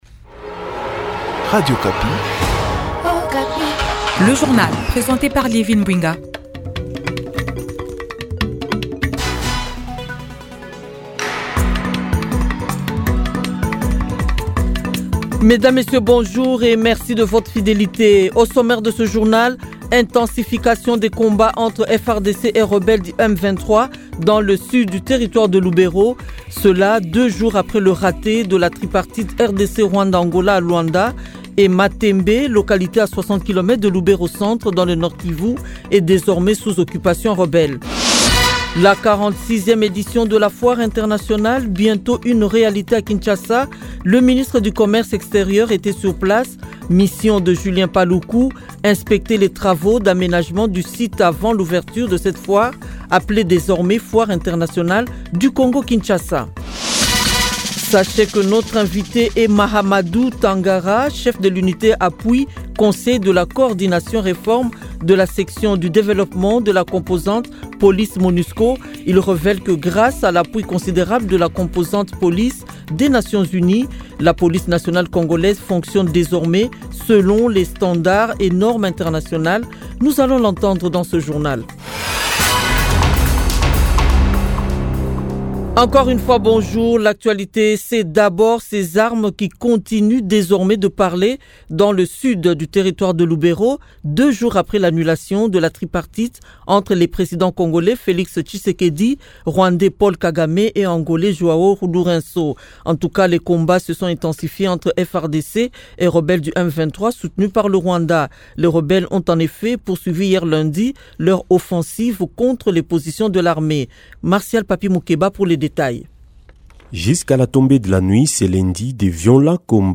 Journal 6h mardi 17 décembre 2024